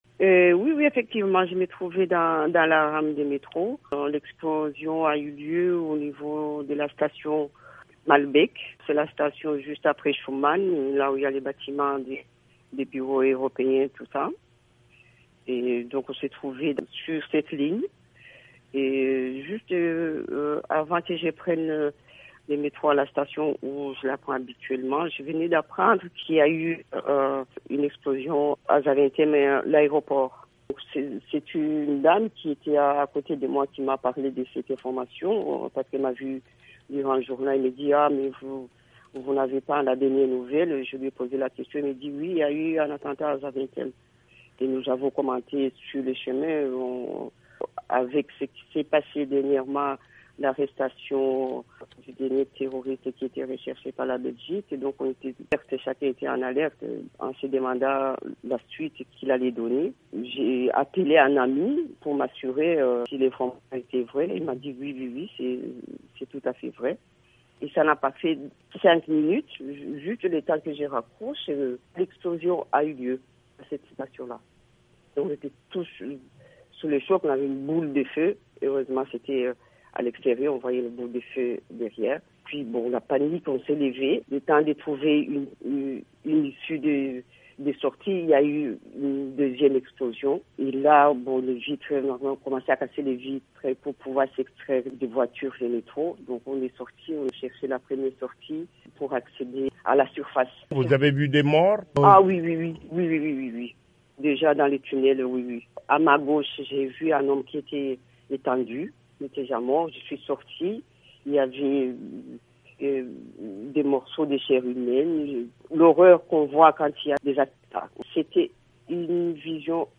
Témoignage d'une rescapée des attentats de Bruxelles